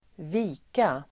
Uttal: [²v'i:ka]